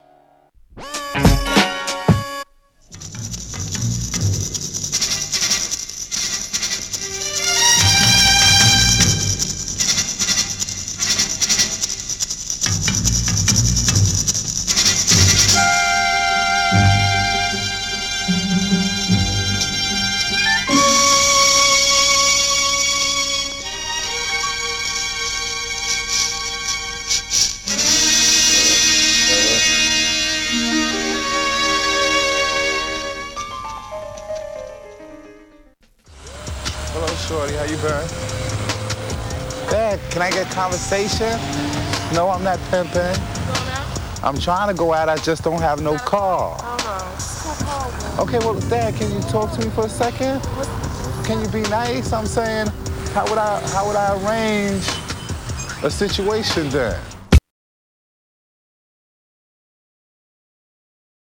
homo-hop